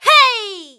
qyh长声2.wav 0:00.00 0:00.80 qyh长声2.wav WAV · 69 KB · 單聲道 (1ch) 下载文件 本站所有音效均采用 CC0 授权 ，可免费用于商业与个人项目，无需署名。
人声采集素材